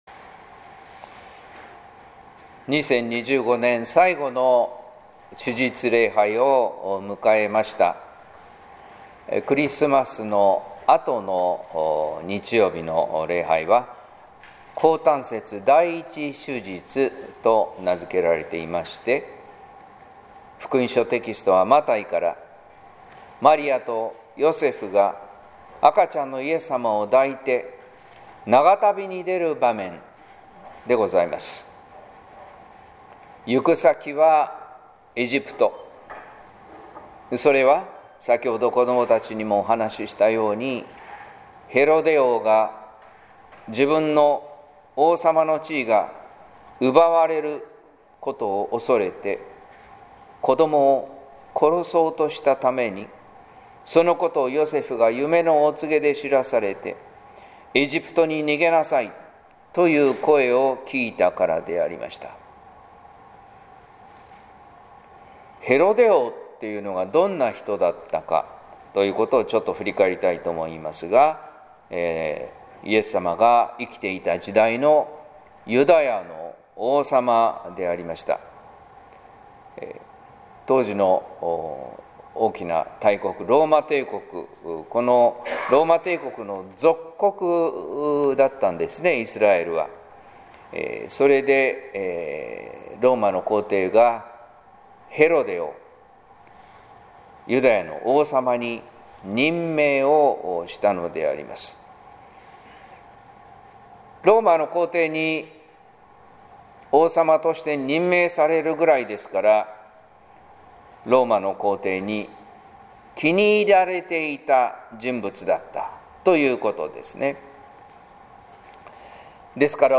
説教「くらしを守り導く光」（音声版）